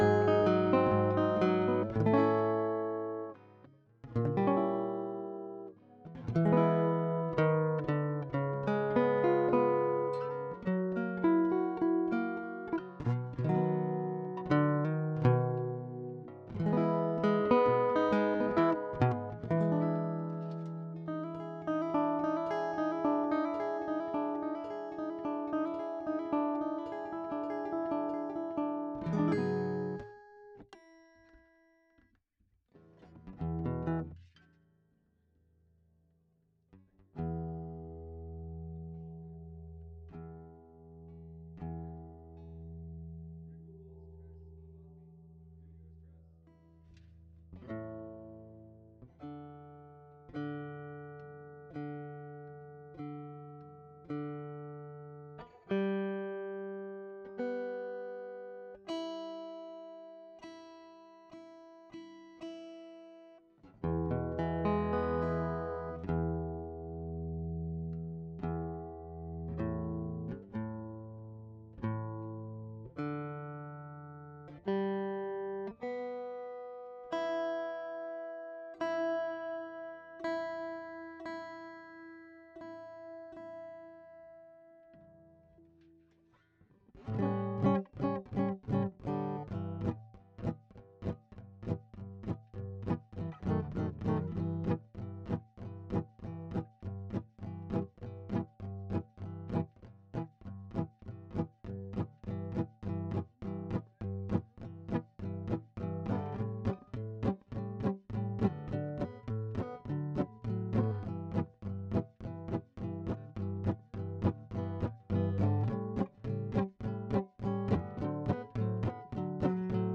GUITAR DI_01.wav